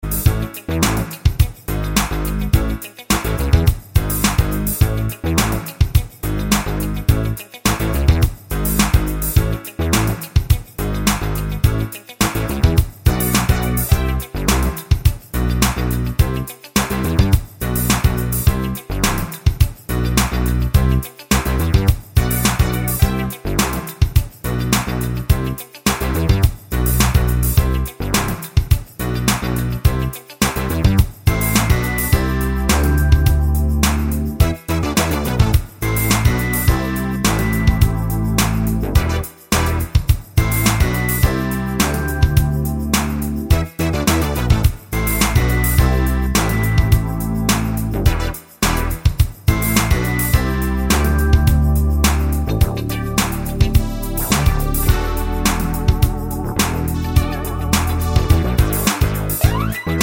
no Backing Vocals Soul / Motown 4:02 Buy £1.50